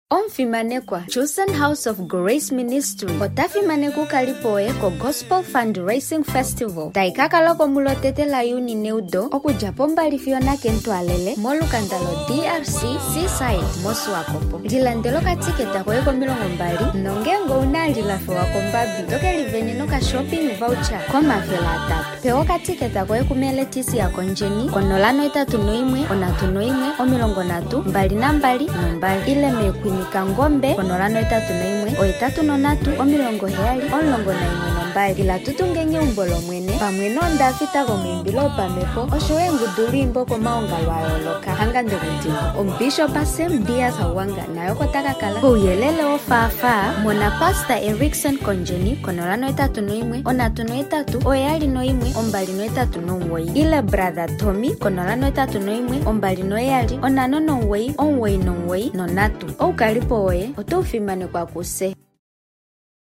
Chosen House of Grace Ministry Gospel Fundraising Festival Advert